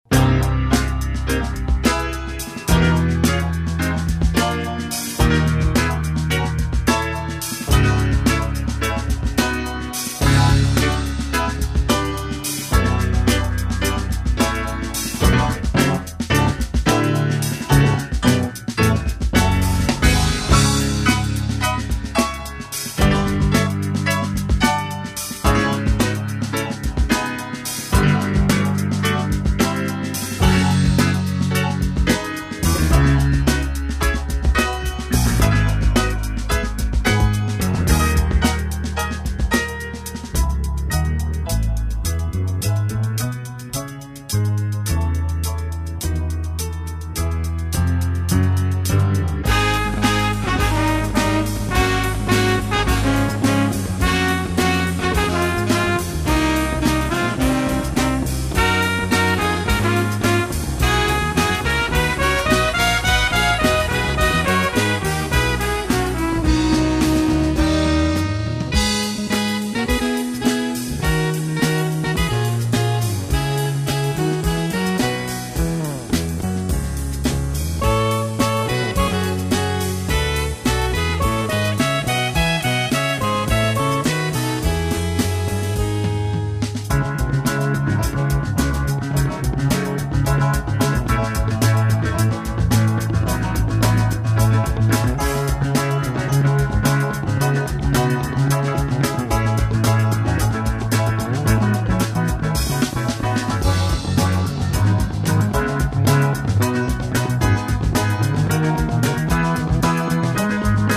newly remastered